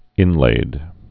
(ĭnlād)